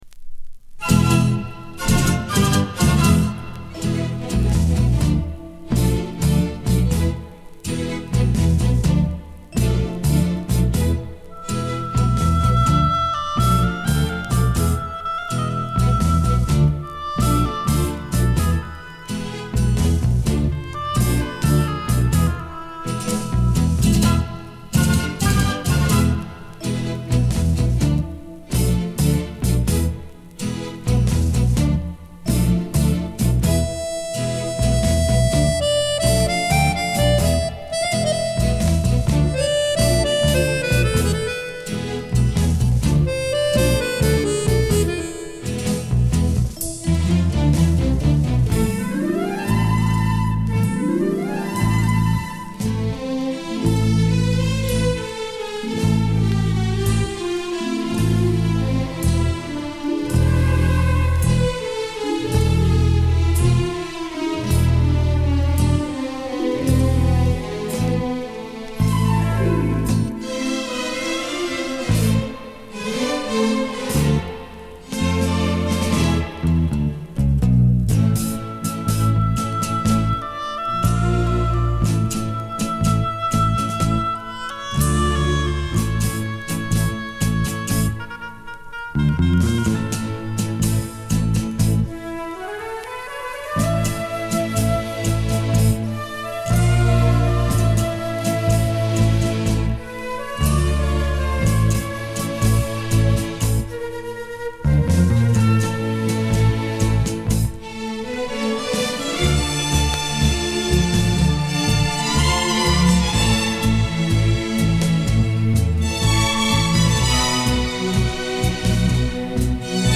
Genre:Latin
Style:Tango, Bolero